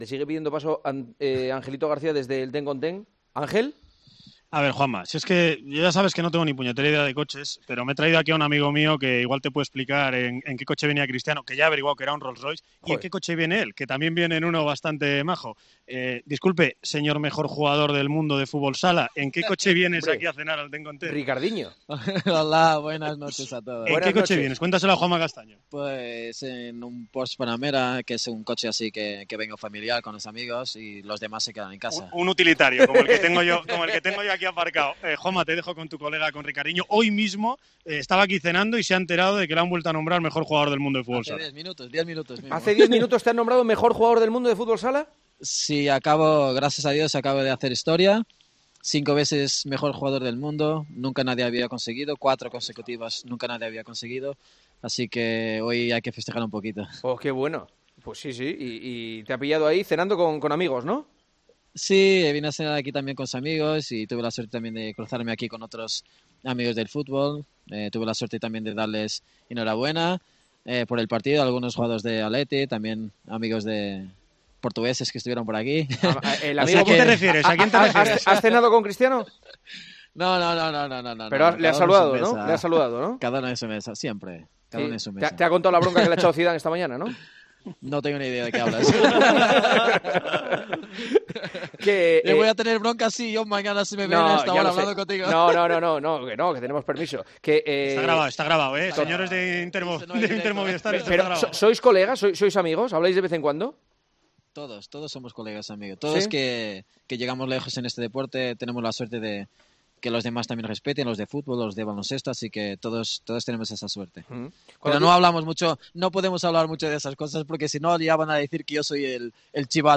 El Partidazo de COPE charló con Ricardinho, jugador de Movistar Inter, a la puerta de un restaurante, justo diez minutos después de conocerse que ha sido nombrado mejor jugador del mundo de fútbol sala: "He hecho historia porque no lo había logrado nadie cinco veces consecutivas. En el restaurante estaba Saúl, que es amigo mío, y seguro que él gana algún Balón de Oro".